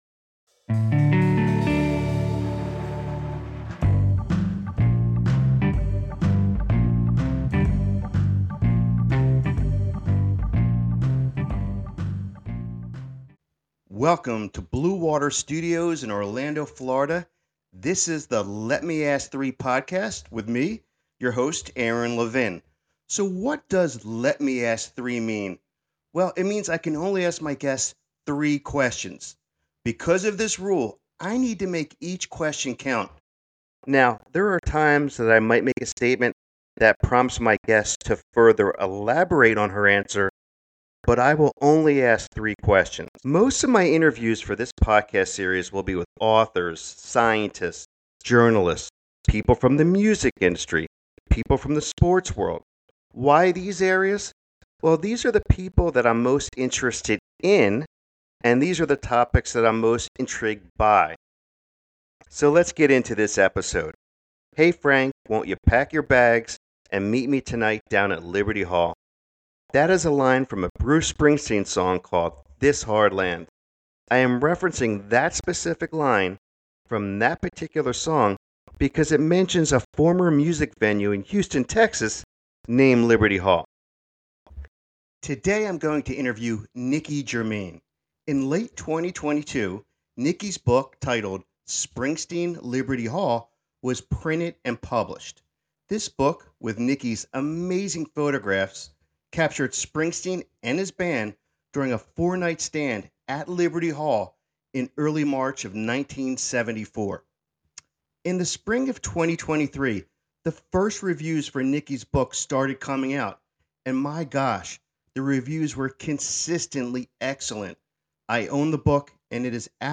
Welcome to Blue Water Studios in Orlando, Florida.